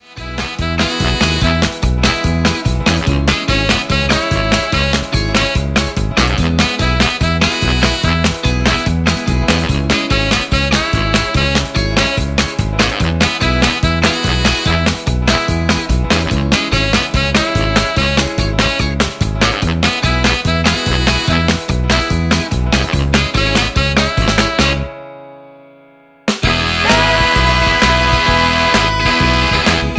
Backing track files: Oldies (1113)